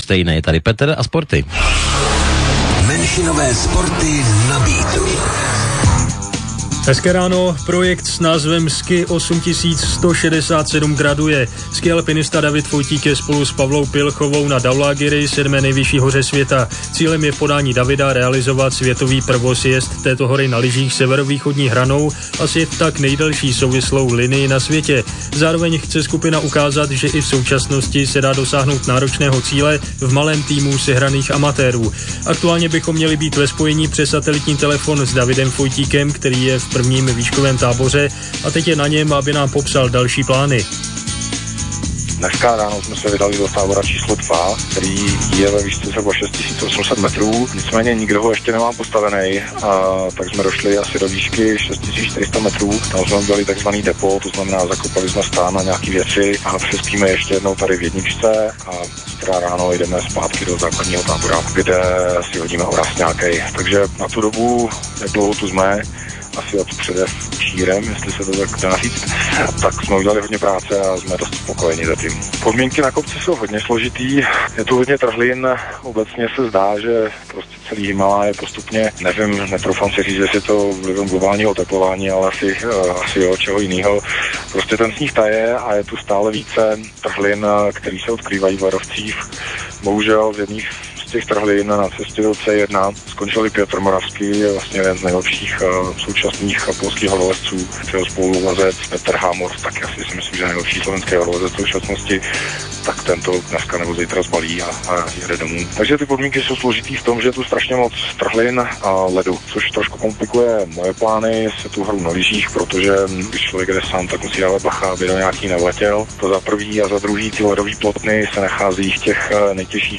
aktualni interview pro Radio Beat